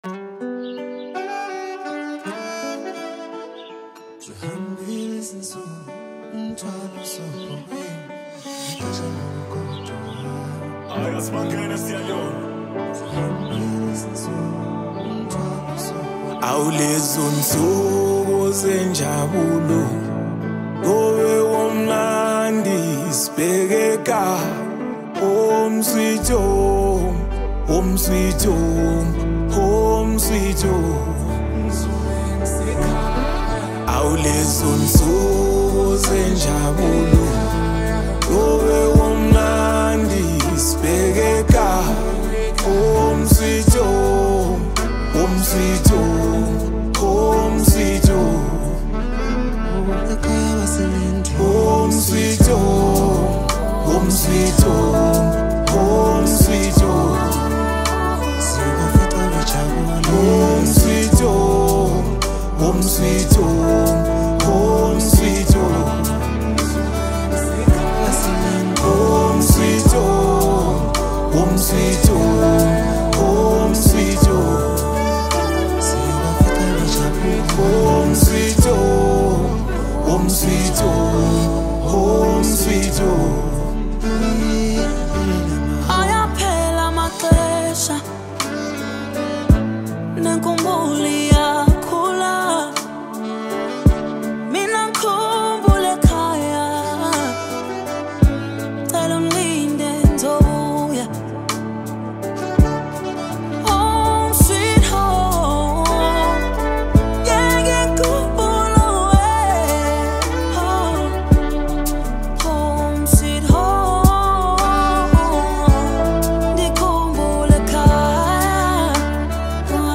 It’s part of his latest Afro-Pop album